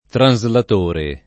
translatore [ tran @ lat 1 re ] → traslatore